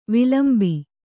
♪ vilambi